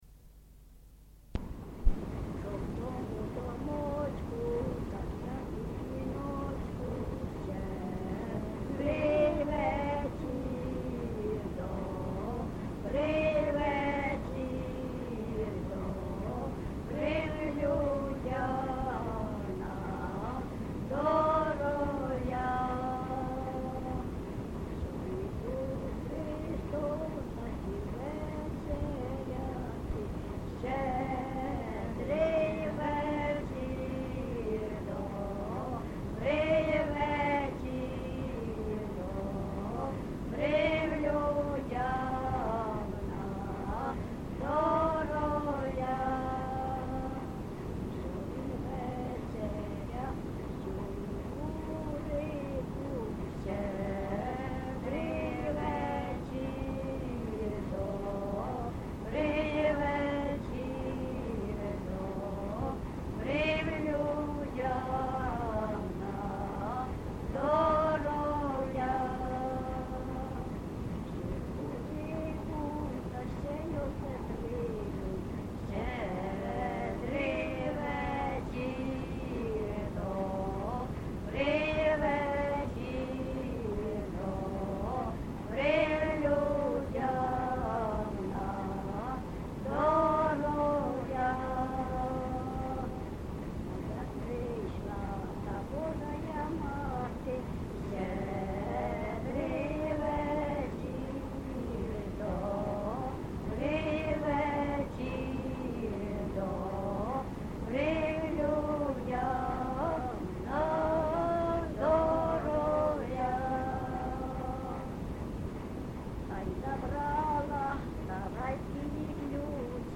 ЖанрЩедрівки
Місце записус. Харківці, Миргородський (Лохвицький) район, Полтавська обл., Україна, Полтавщина